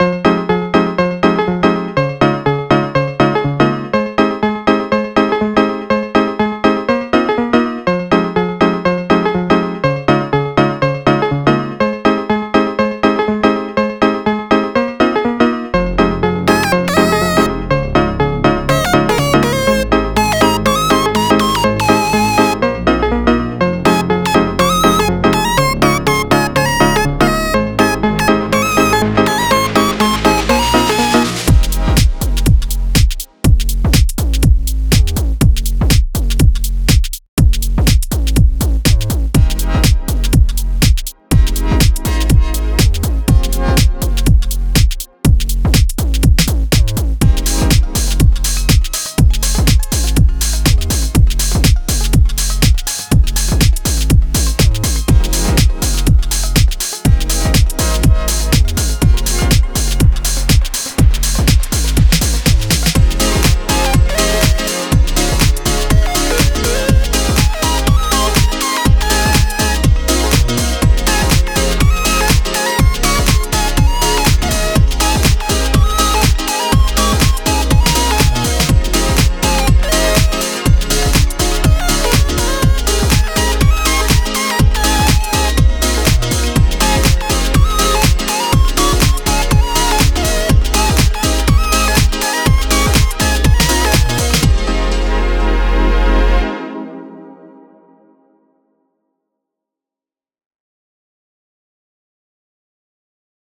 122 BPM